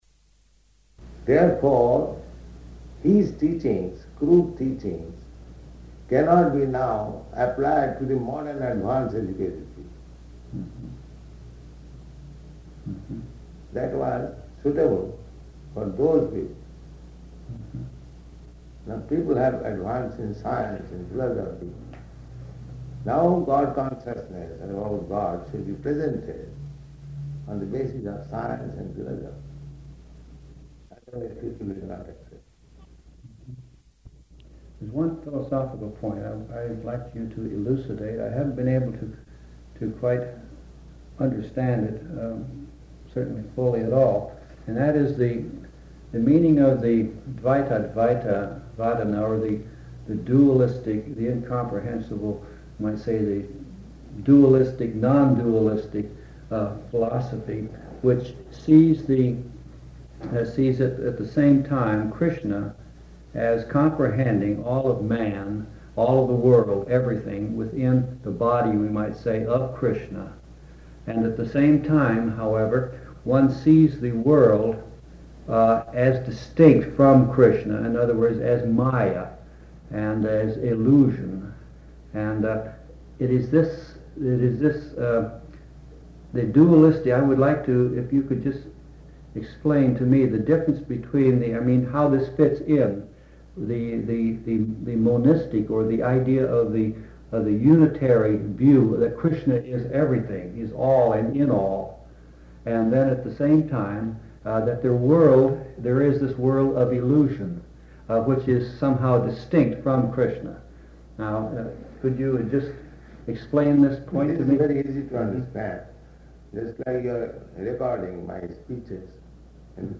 Room Conversation [partially recorded]
Location: London